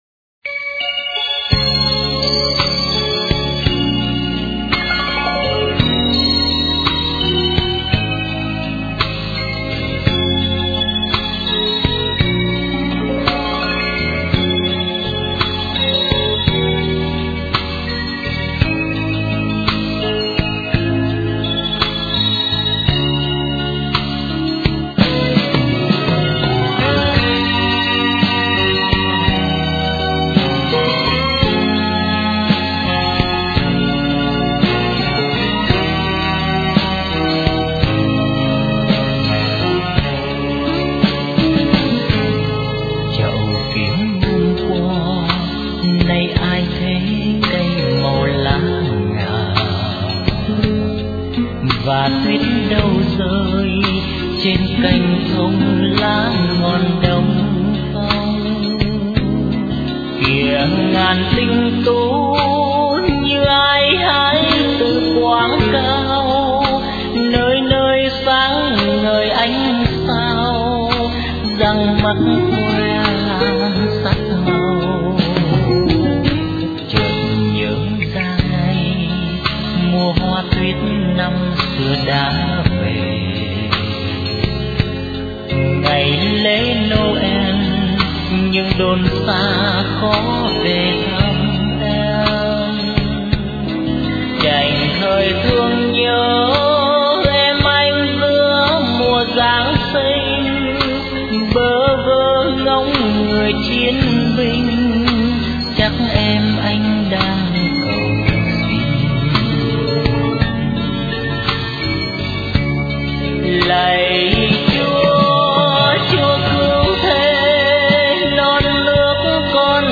* Thể loại: Noel